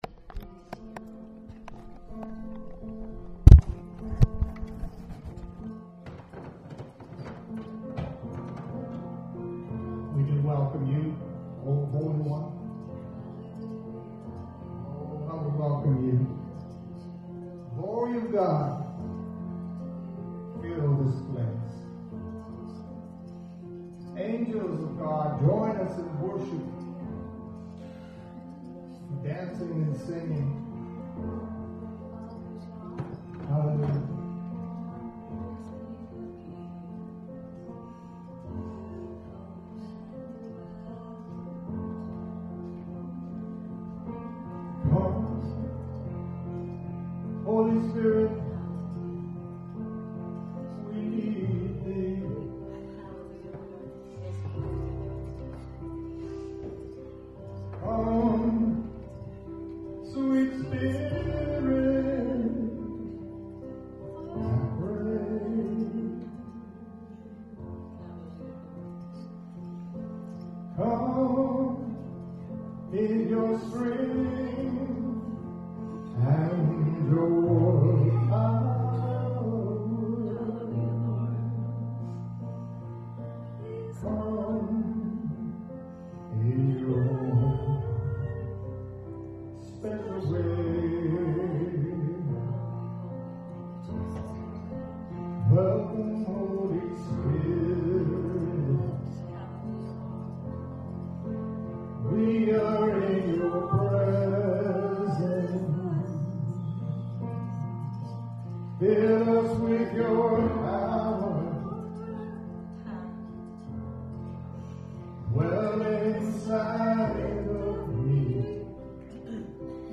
worship 1123.mp3